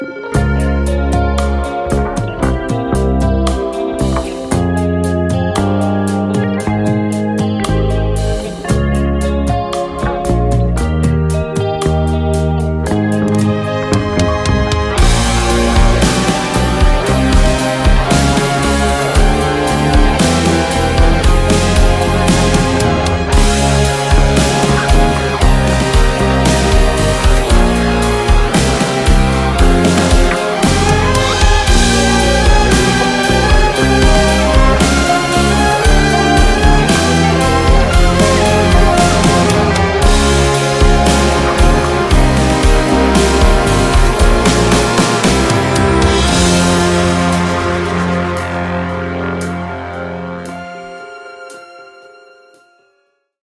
Поэтому и музыка такая светлая и оптимистичная.